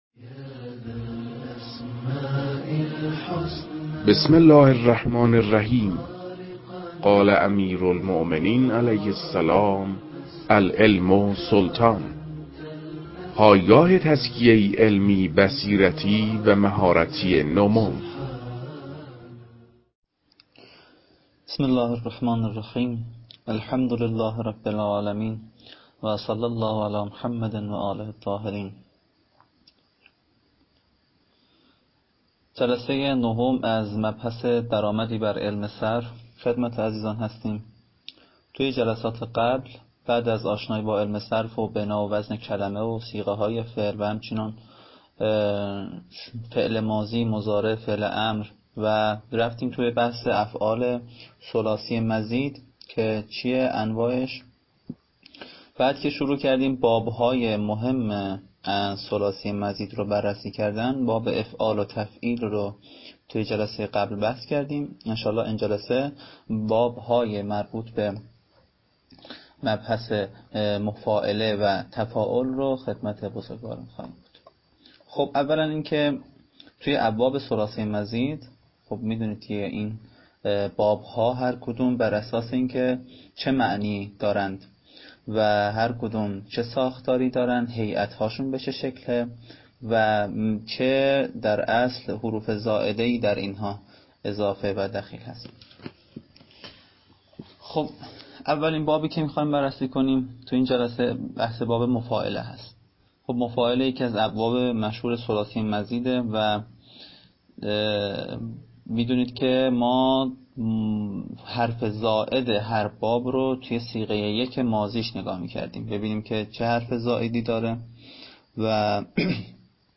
در این بخش، کتاب «درآمدی بر صرف» که اولین کتاب در مرحلۀ آشنایی با علم صرف است، به صورت ترتیب مباحث کتاب، تدریس می‌شود.
در تدریس این کتاب- با توجه به سطح آشنایی کتاب- سعی شده است، مطالب به صورت روان و در حد آشنایی ارائه شود.